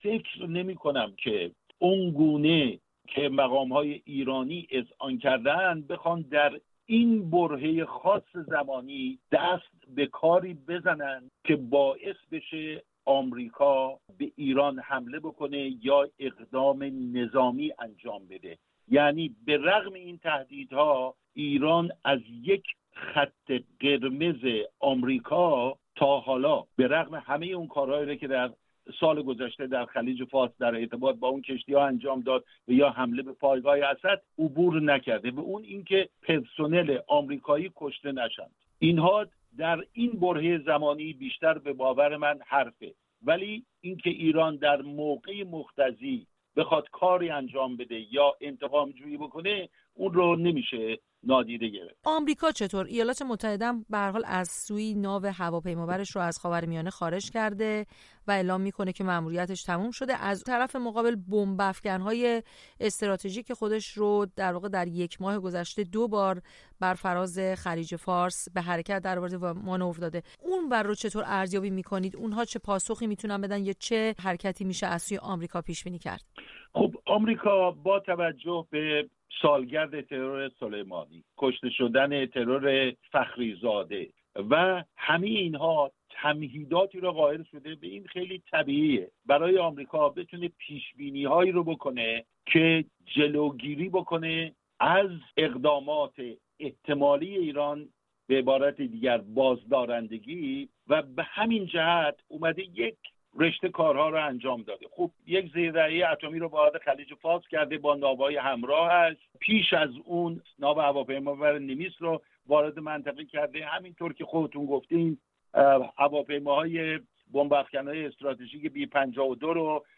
گفت و گویی